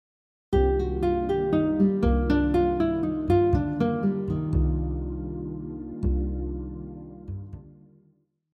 Lastly, example 5 incorporates the minor 7 arpeggio with the blues scale and some chromatic notes.
minor 7 arpeggio example 5